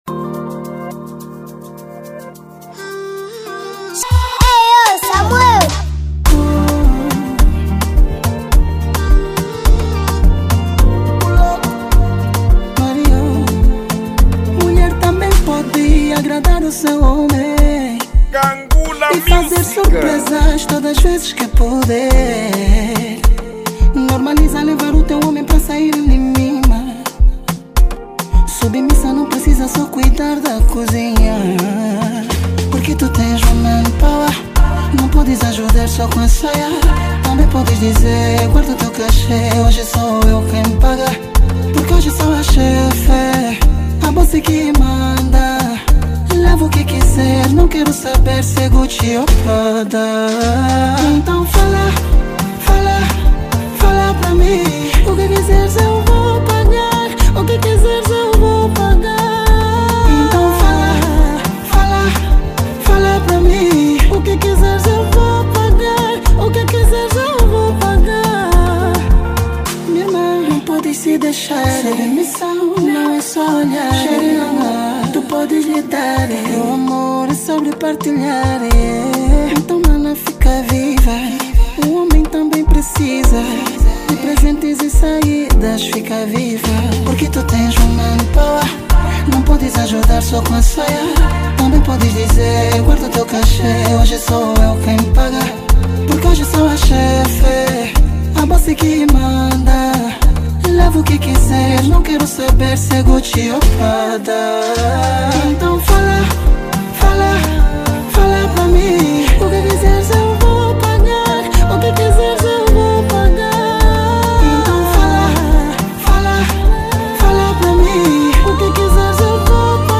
| Afrobeat